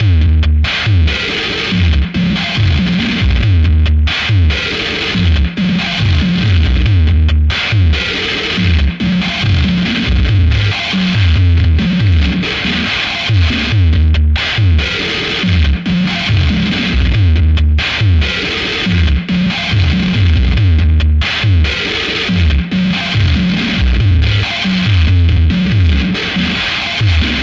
Attack loopz 2 " 75 bpm Acoustic New Metal Wall Attack loop 6
我使用Acoustic kit来创建循环，并创建了8个不同顺序的循环，速度为75 BPM，长度为4/4的8个措施。
各种效果都是相当失真。
标签： 4 75bpm drumloop
声道立体声